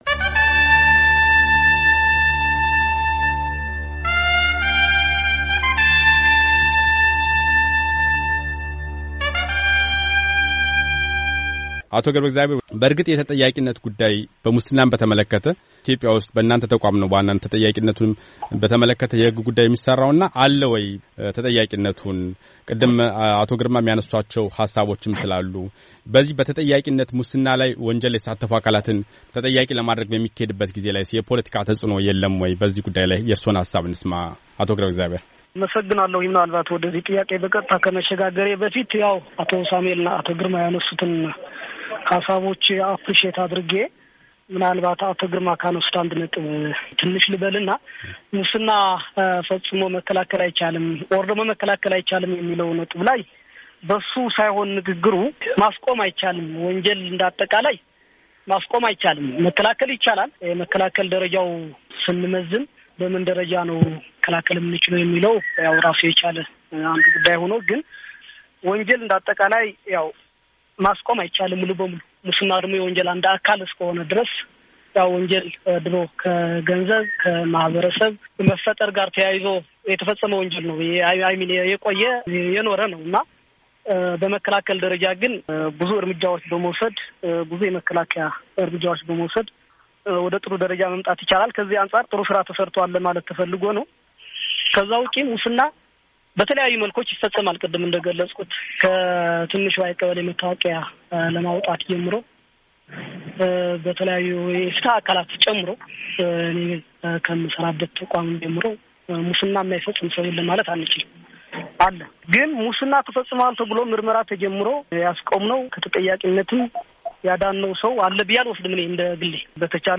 በተከታዩ ክፍል ደግሞ ሙስናን ለመከላከል በሃገር አቀፍ ደረጃ ያለው ዝግጁነትና የመፍተሄ ሃሳቦች ላይ ተወያይተዋል፡